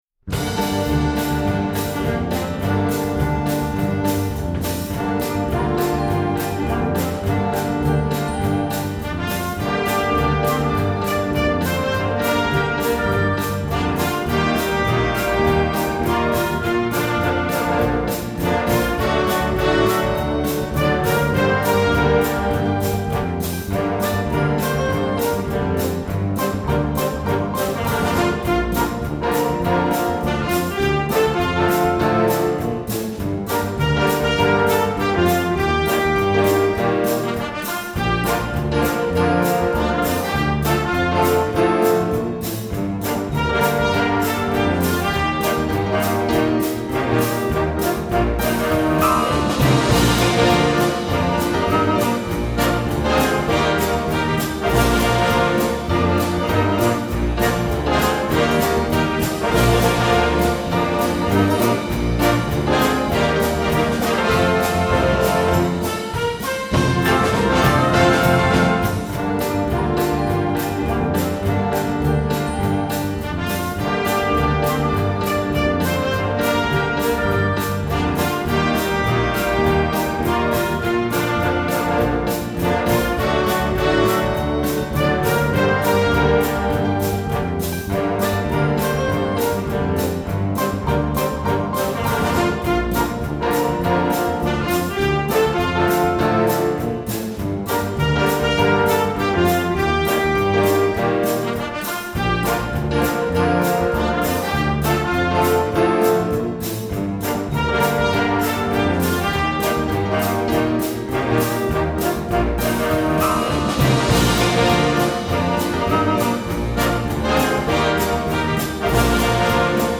2:19 Minuten Besetzung: Blasorchester PDF